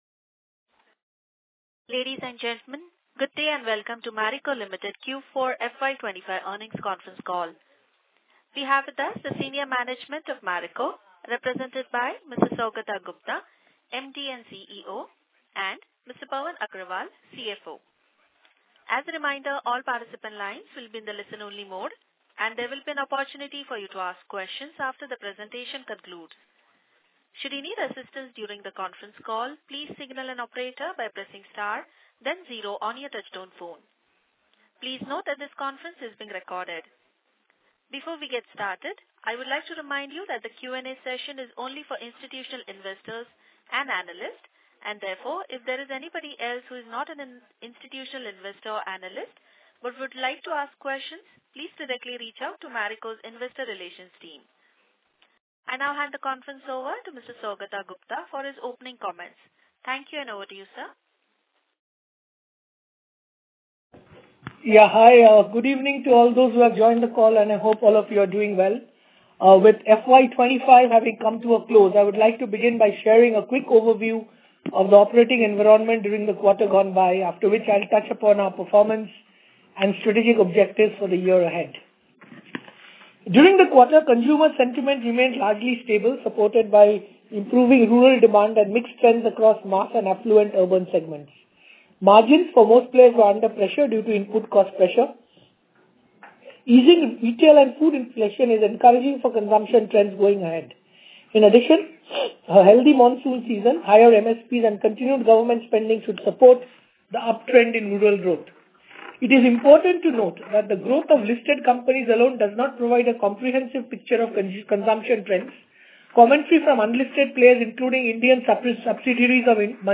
Q4FY25 - Earnings Call Recording